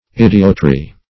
idiotry - definition of idiotry - synonyms, pronunciation, spelling from Free Dictionary Search Result for " idiotry" : The Collaborative International Dictionary of English v.0.48: Idiotry \Id"i*ot*ry\, n. Idiocy.